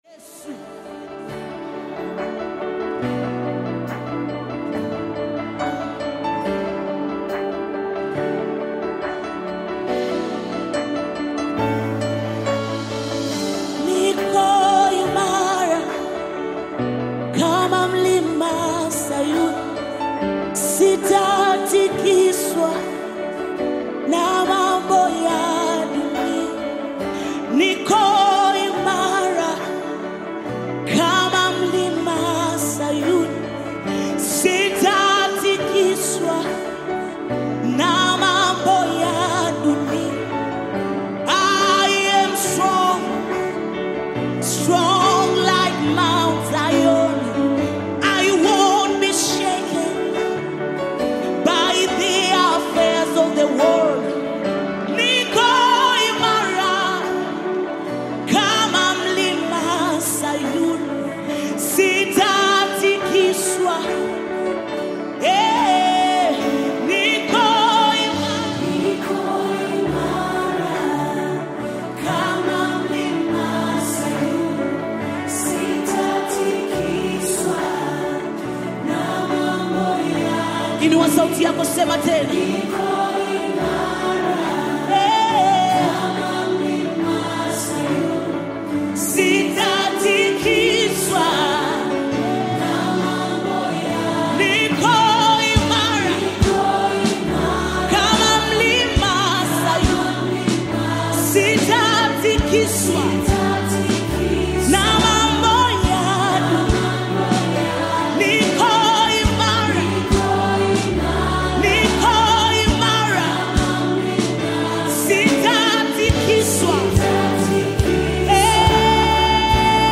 Nyimbo za Dini Praise music
Praise Gospel music track